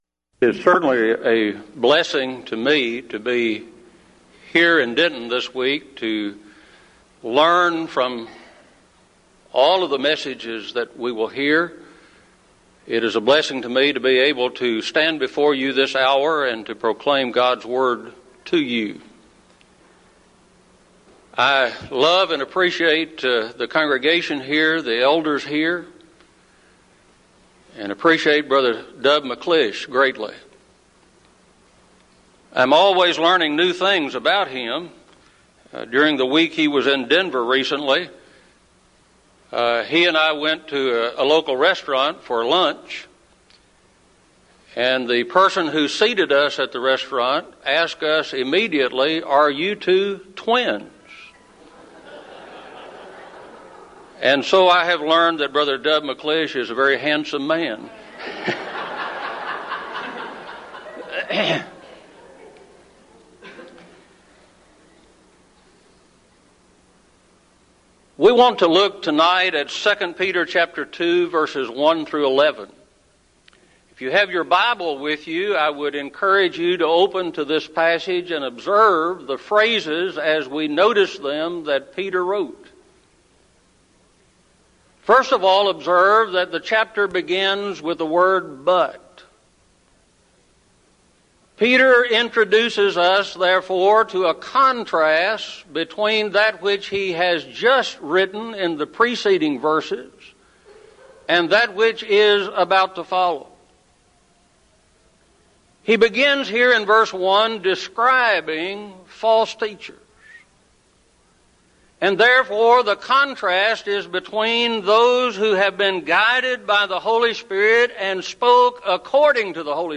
Event: 1998 Denton Lectures Theme/Title: Studies in the Books of I, II Peter and Jude
lecture